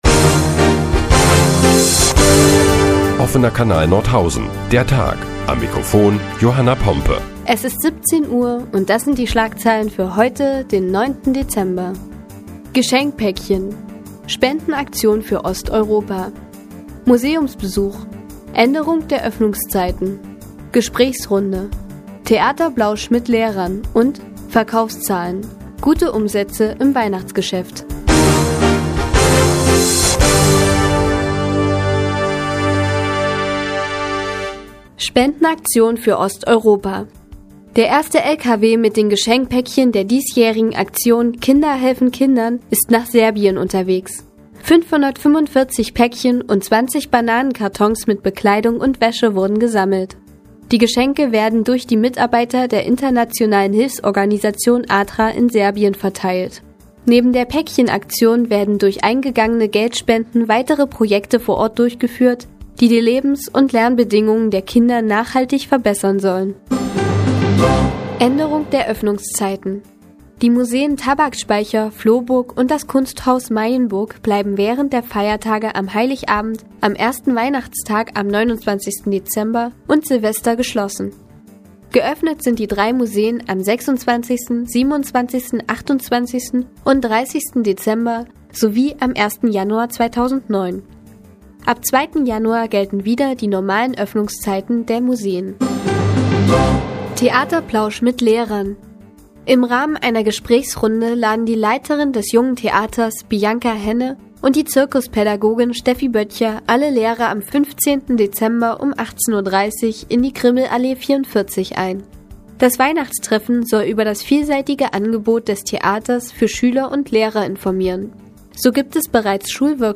Die tägliche Nachrichtensendung des OKN ist nun auch in der nnz zu hören. Heute geht es unter anderem um die Spendenaktion für Kinder in Osteuropa und steigende Umsätze Thüringer Händler in der Weihnachtszeit.